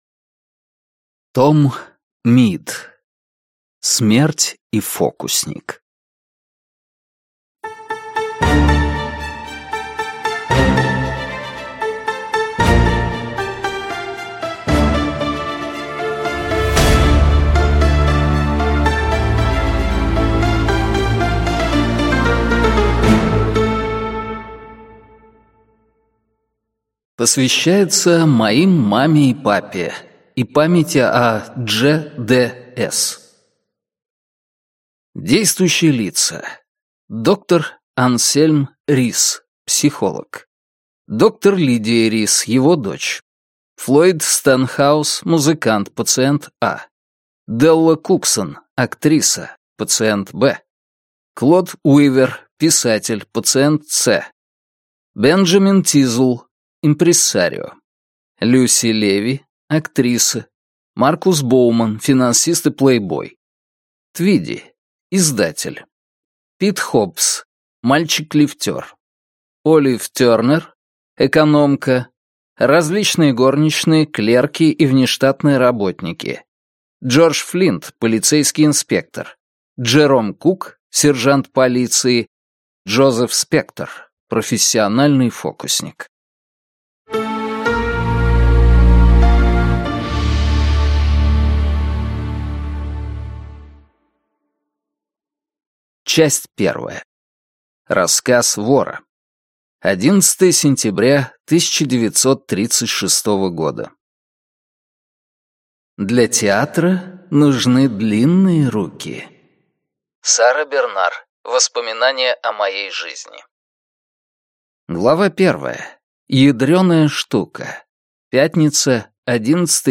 Аудиокнига Смерть и фокусник | Библиотека аудиокниг